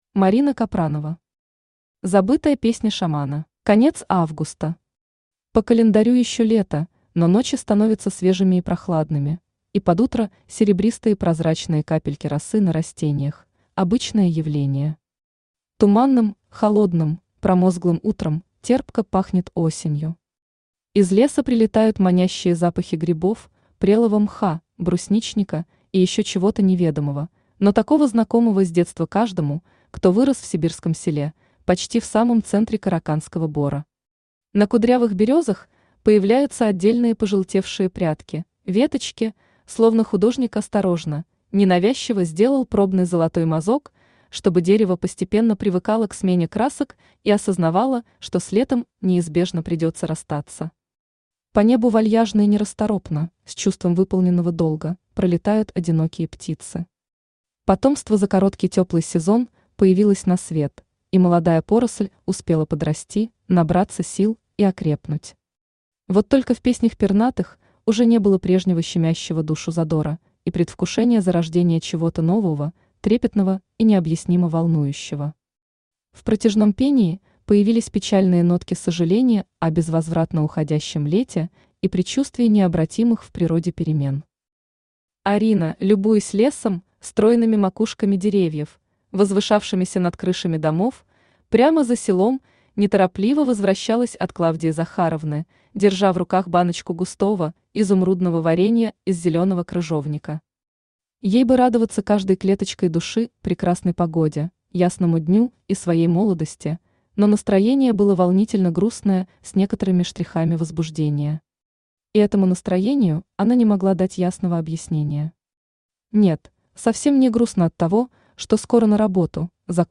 Аудиокнига Забытая песня шамана | Библиотека аудиокниг
Aудиокнига Забытая песня шамана Автор Марина Капранова Читает аудиокнигу Авточтец ЛитРес.